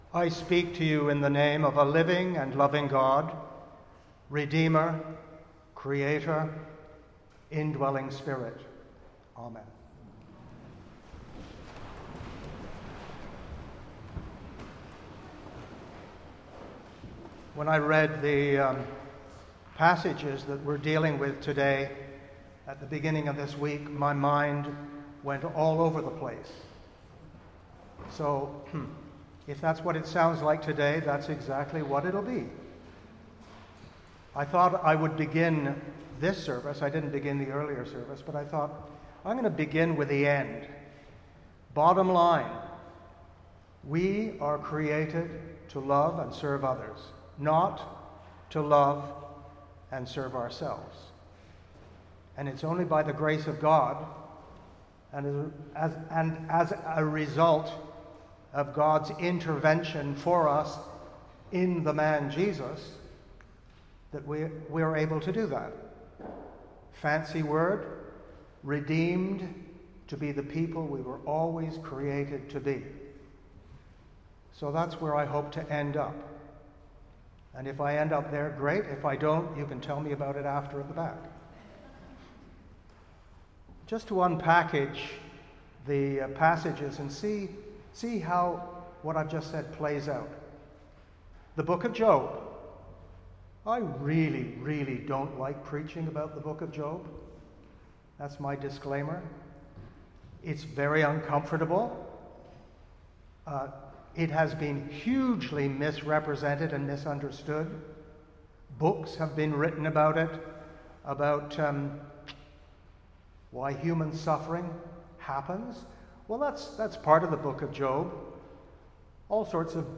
Sermons | Christ Church Cathedral Ottawa